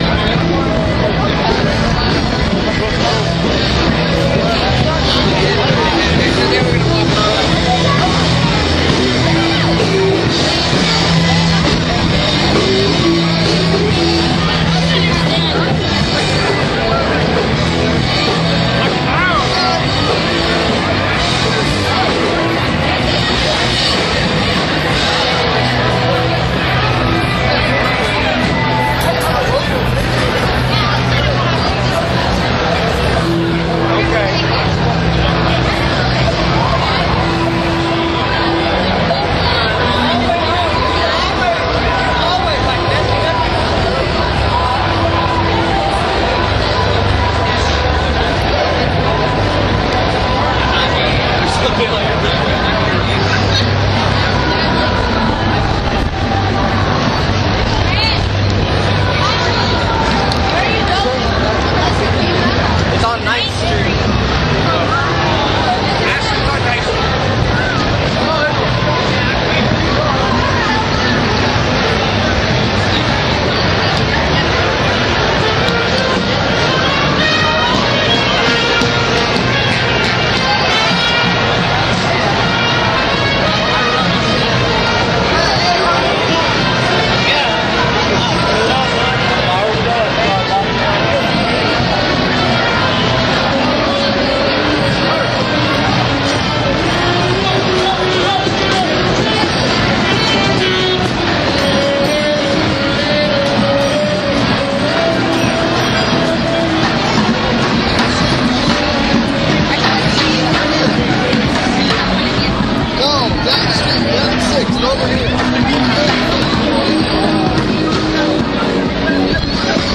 Sounds from scattered venues churn together in a vortex of vibrations and you press on. It is impossible to separate the knotted threads of noise into anything musical.
sxswcrowd2013.mp3